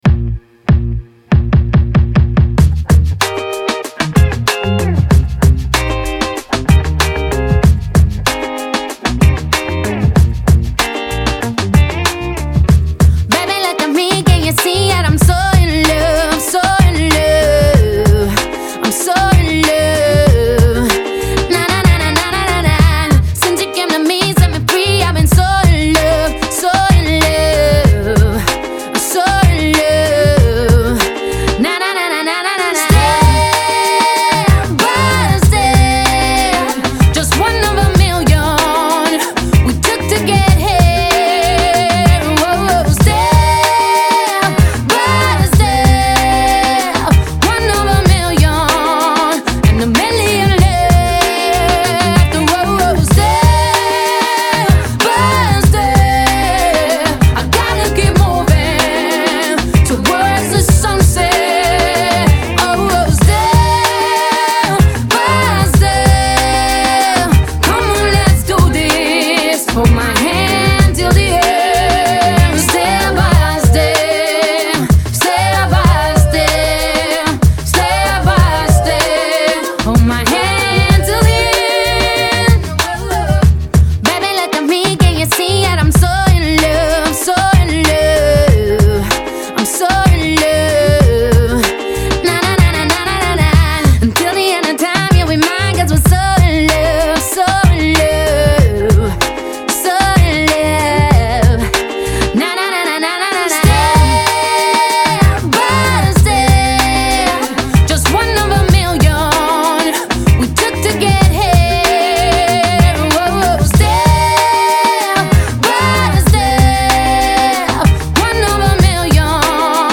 мощная поп-баллада